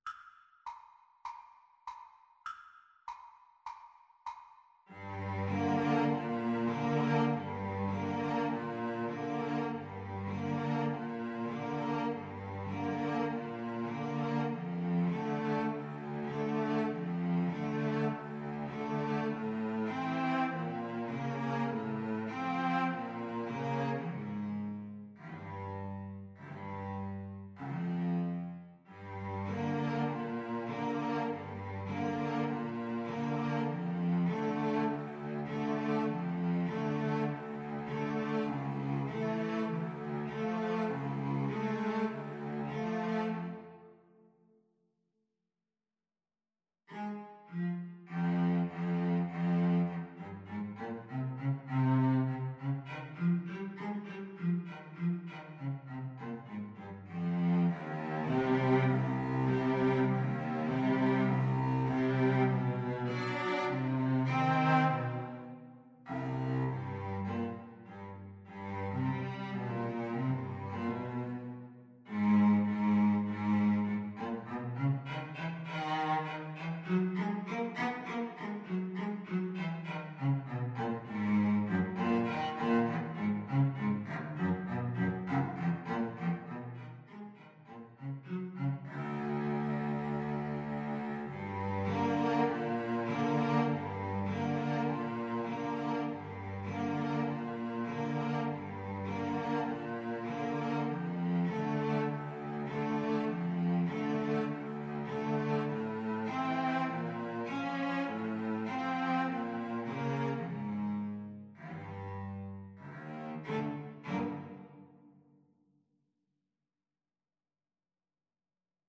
Cello Duet  (View more Advanced Cello Duet Music)
Classical (View more Classical Cello Duet Music)